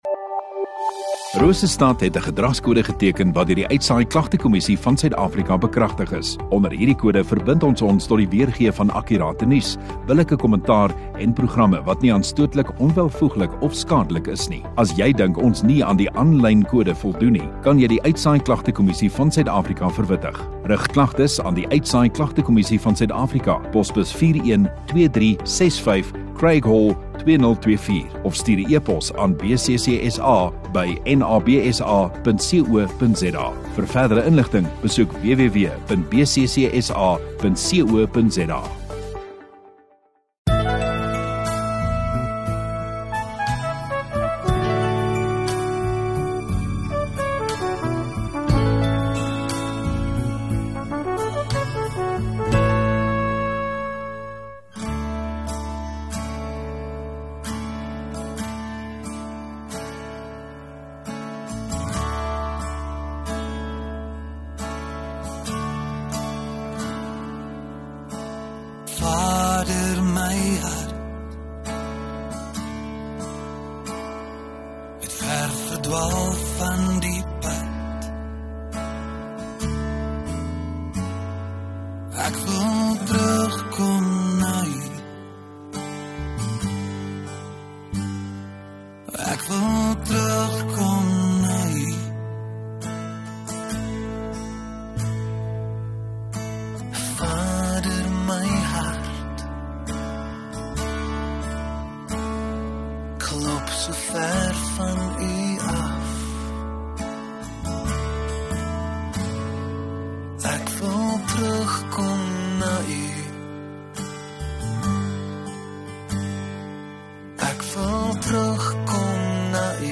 11 Aug Sondagaand Erediens